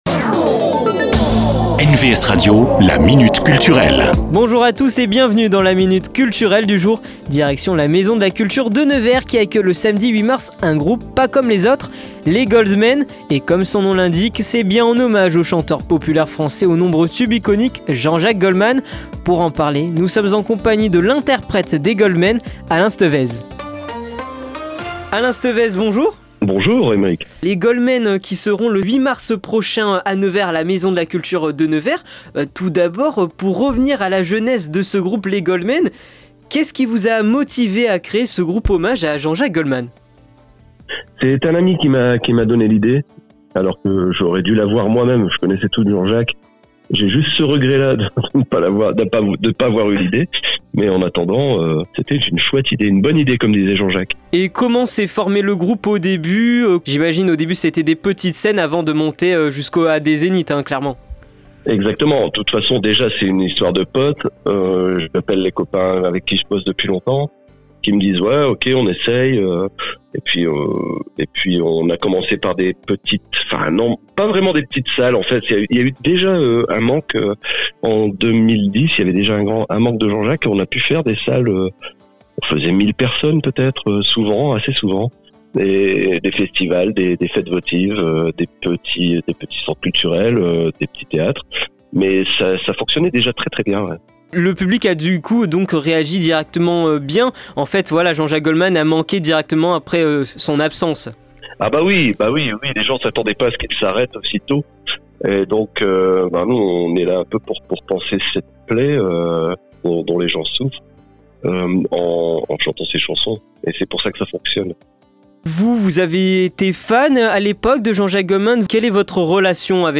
La Minute Culture, rencontre avec les acteurs culturels de votre territoire.